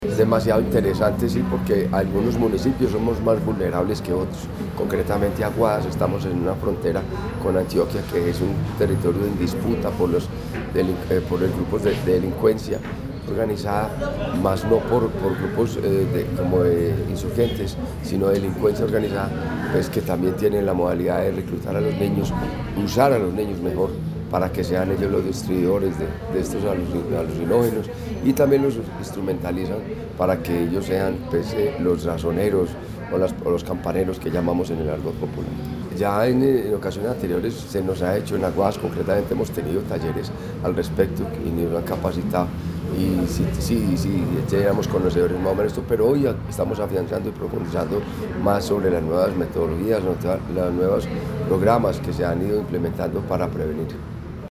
Fabio Gómez Mejía, alcalde del municipio de Aguadas.
Fabio-Gomez-Mejia-alcalde-del-municipio-de-Aguadas.mp3